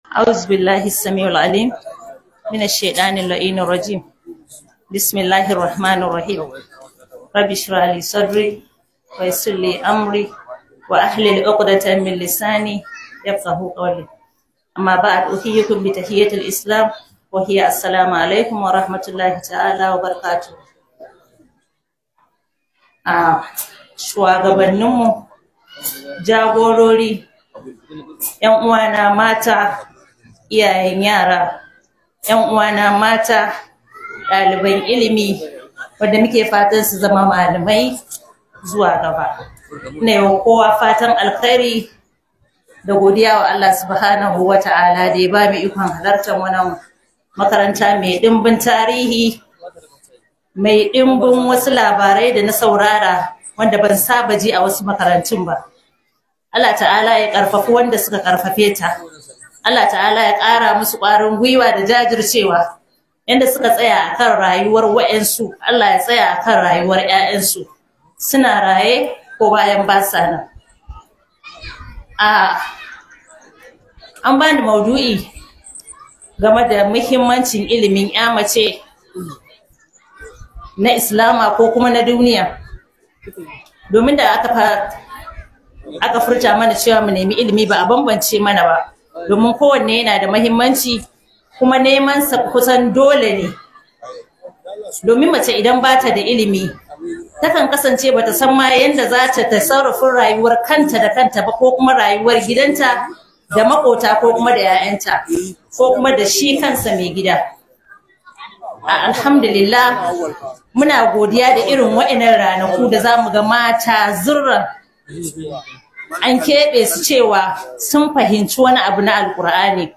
Muhimmancin Ilimin 'Ya Mace - Muhadara